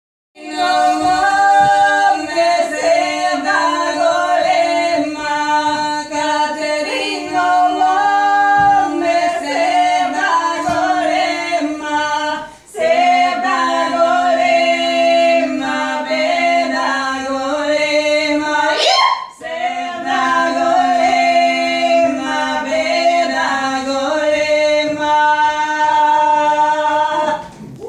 Hier hatten wir einen Raum von 50 Leuten, die zu Beginn (vermutlich) weder die Texte noch die Melodien kannten, und die am Ende mehrstimmig oder im Kanon verschiedene Lieder sangen.
(z.B. Katherina Mome (bulgarisches Volkslied), Loy Yisa Goy (Hebräisches Lied), Osi boku (afrikan.